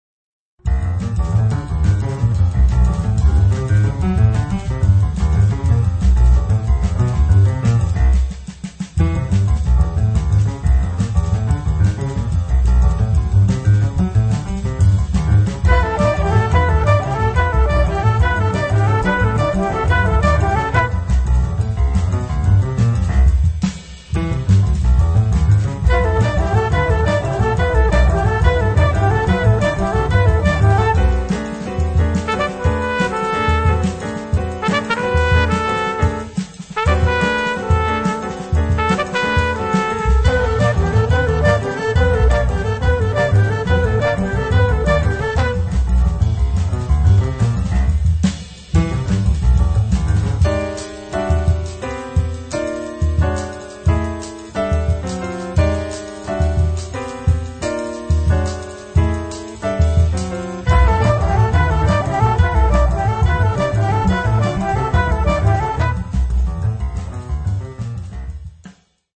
flauto & alto flauto
tromba & flicorno
alto sax & clarinetto basso
corno francese
piano & piano elettrico
contrabbasso
batteria